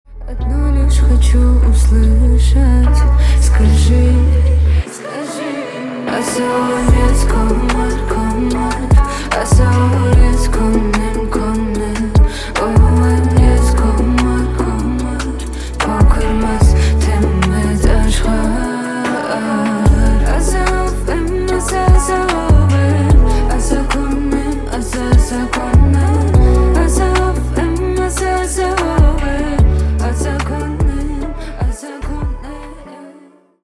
поп , романтические , красивый женский голос